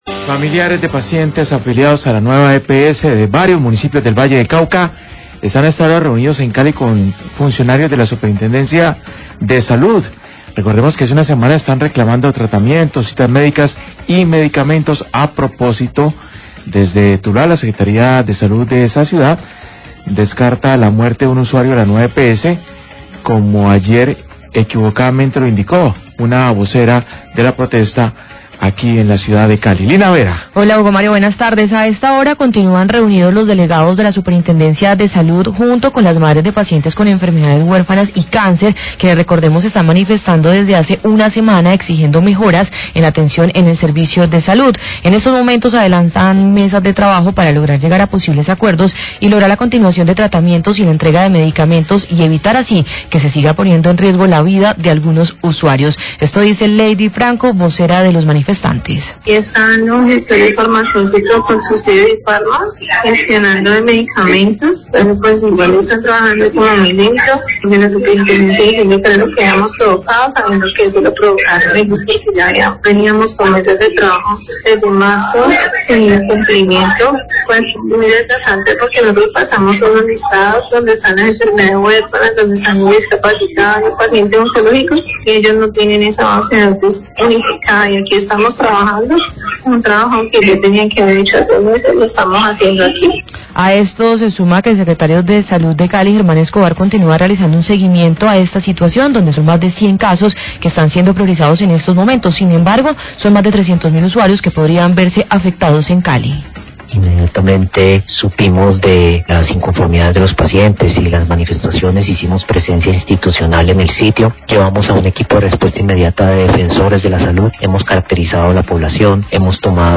Radio
Madres de pacientes con cáncer y enfermedfades huérfanas que protestan y bloquean Nuava EPS se encuentran reunidas con Superintendencia de Salud. El Secretario de salud, Germán Escobarm habla de las acciones de seguimiento y vigilancia de la situación.